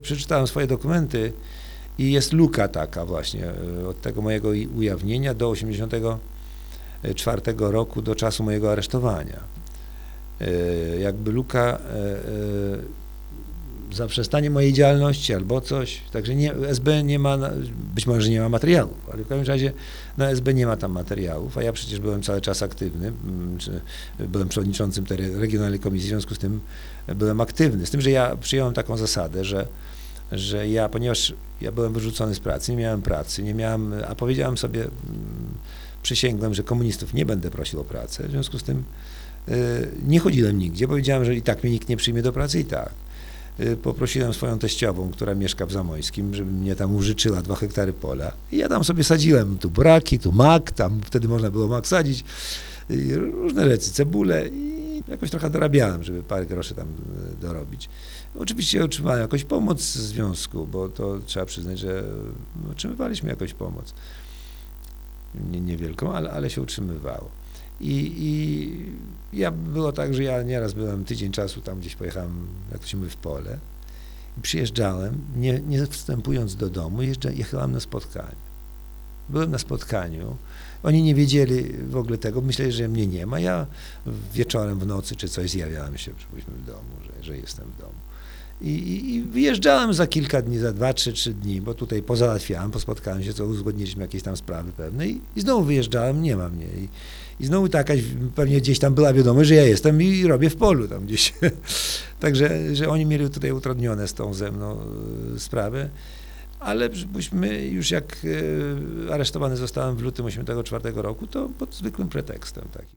Relacja mówiona zarejestrowana w ramach Programu Historia Mówiona realizowanego w Ośrodku